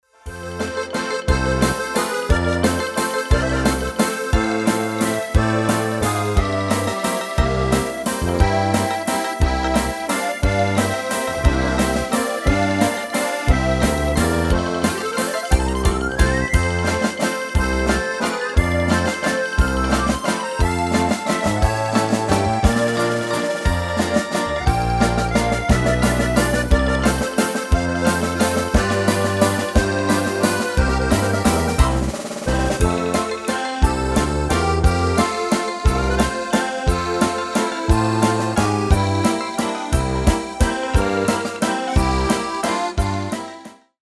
Extended MIDI File Euro 12.00
Demo's zijn eigen opnames van onze digitale arrangementen.